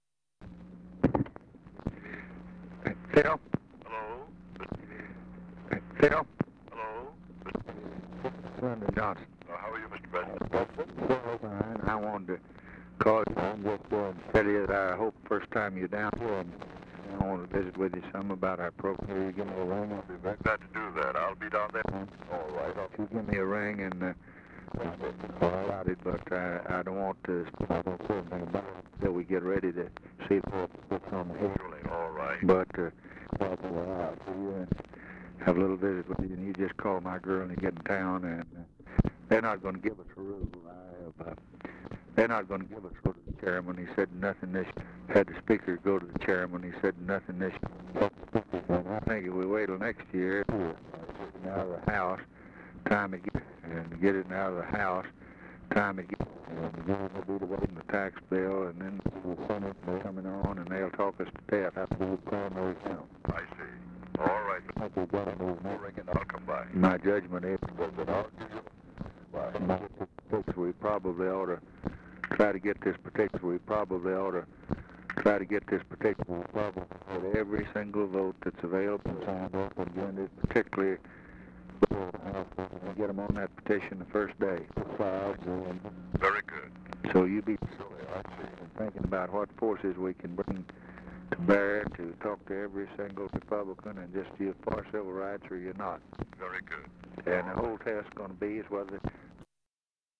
Conversation with A. PHILIP RANDOLPH, November 29, 1963
Secret White House Tapes